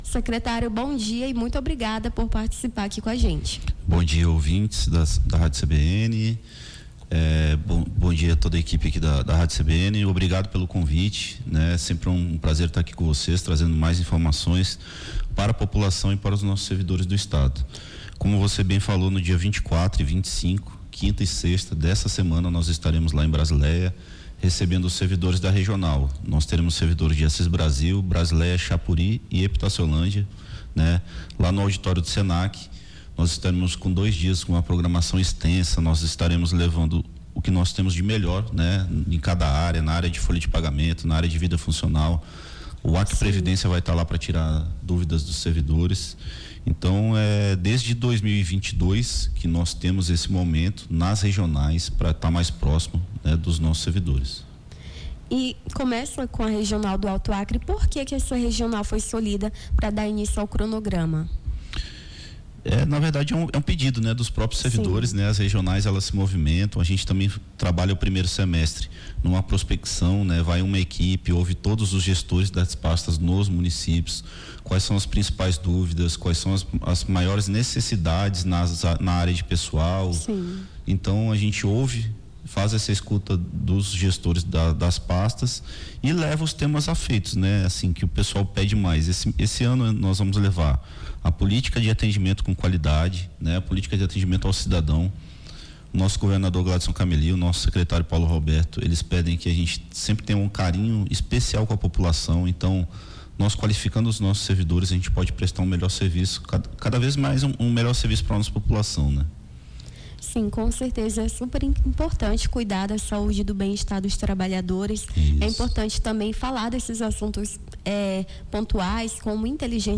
Baixar Esta Trilha Nome do Artista - CENSURA - ENTREVISTA (RH ITINERANTE) 21-07-25.mp3 Foto: Ascom/Sead Facebook Twitter LinkedIn Whatsapp Whatsapp Tópicos Rio Branco Acre RH Itinerante Alto Acre retorno